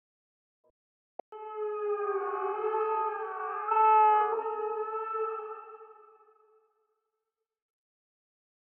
Po użyciu, odtwarzacz kaset będzie odtwarzał dźwięki flamingów u graczy znajdujących się w pobliżu odtwarzacza.
Odtwarzacz kasetowy Flamingo Call
Tape_call_of_the_flingos.mp3